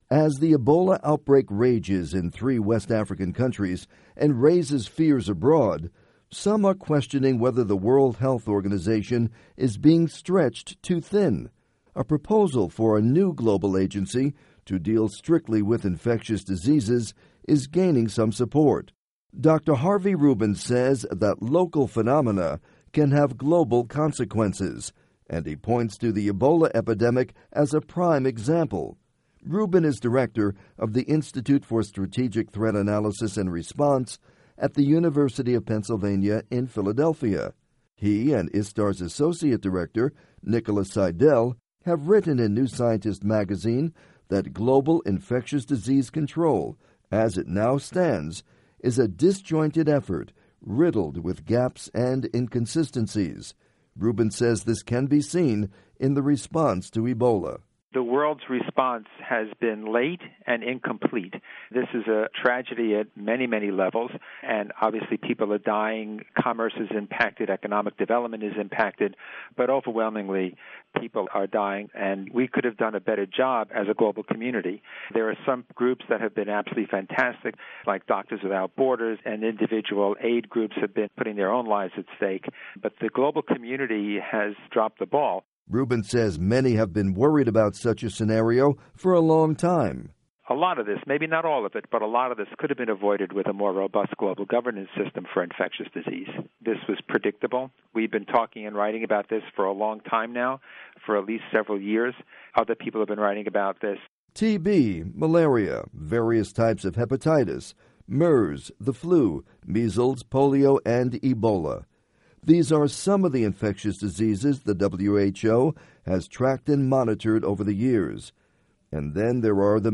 report on new response to infectious diseases